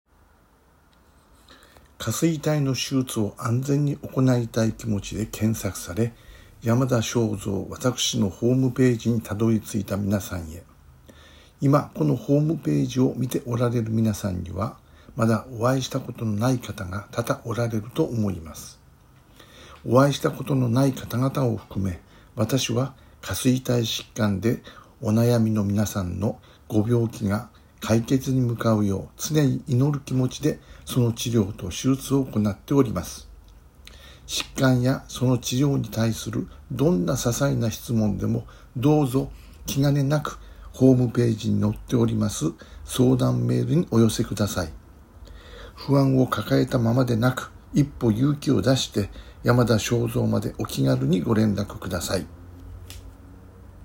音声メッセージ